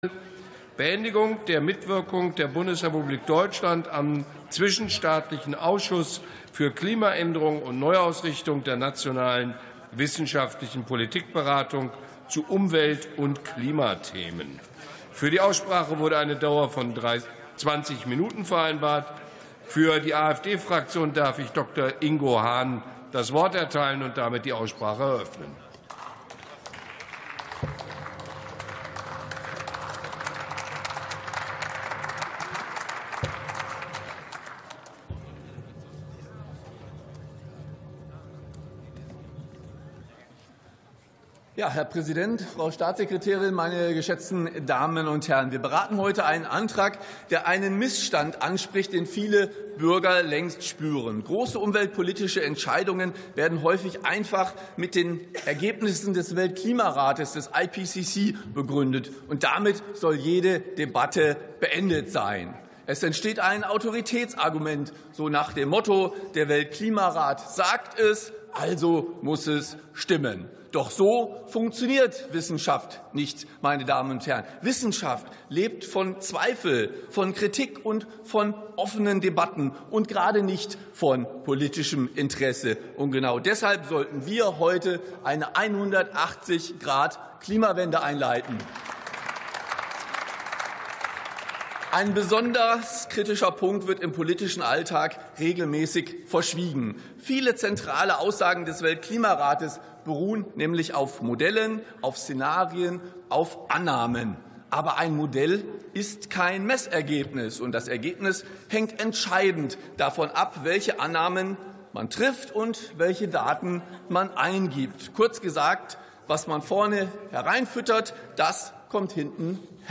Sitzung vom 05.03.2026. TOP 24: Beendigung der Mitwirkung am Weltklimarat (IPCC) ~ Plenarsitzungen - Audio Podcasts Podcast